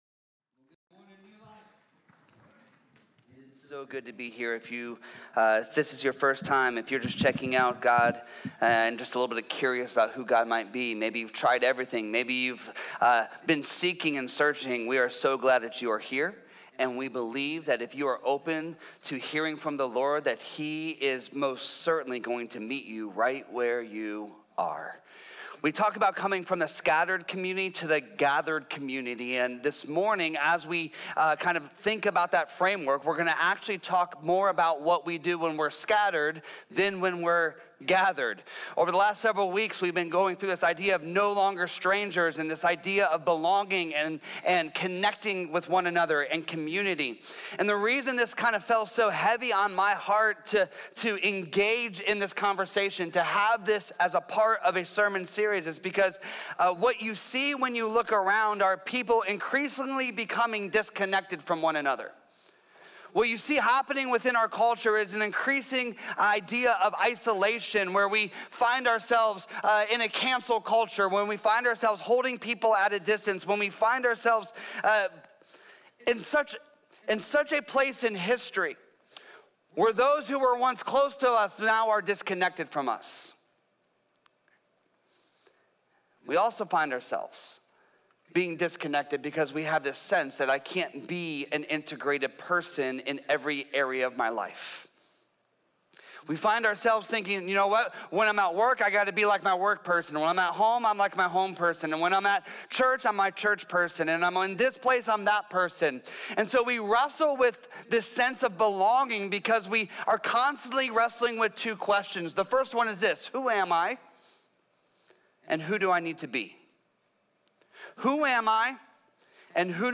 A message from the series "No Longer Strangers."